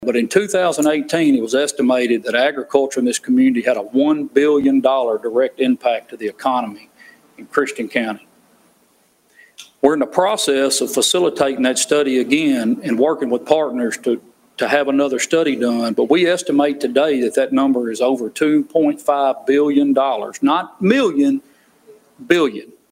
Agriculture leaders and related industries addressed Hopkinsville City Council about the issue Tuesday night.